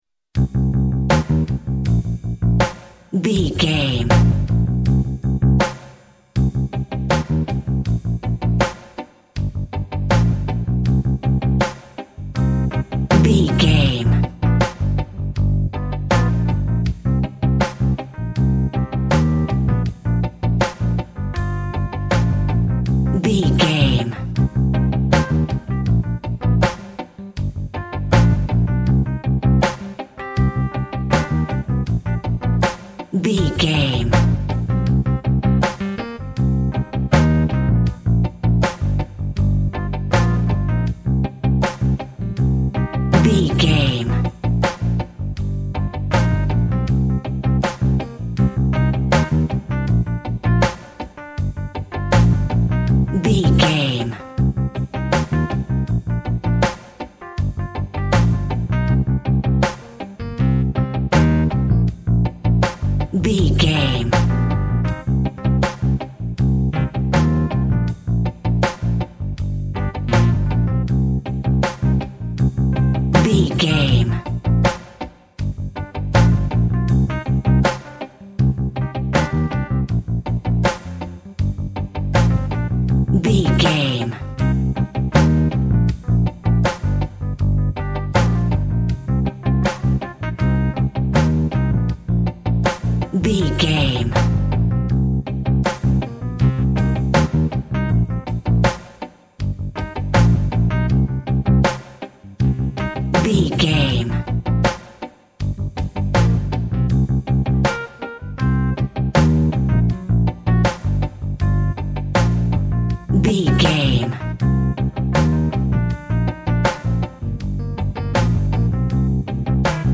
Also with small elements of Dub and Rasta music.
Aeolian/Minor
Slow
tropical
drums
bass
guitar
brass
steel drum